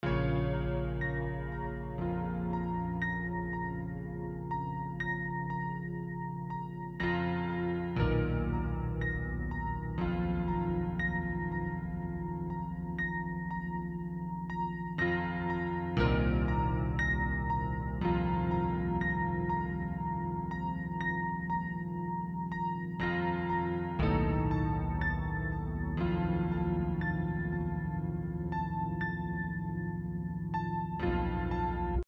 Den Abschluss der E-Piano-Reihe bildet das Yamaha CP 70. Anders als Rhodes, Wurlitzer und Co setzt es auf eine echte Piano-Hammermechanik und Saiten, deren Schwingungen über Piezo-Tonabnehmer abgenommen und über einen internen Verstärker geschickt werden.